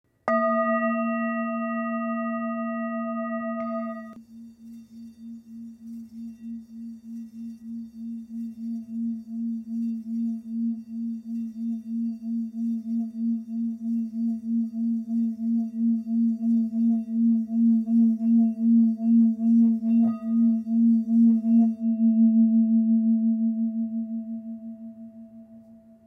Tepaná tibetská mísa Chuto o hmotnosti 881 g, včetně paličky
Zvuk tibetské misy Chuto si můžete poslechnout zde
tibetska_misa_s20.mp3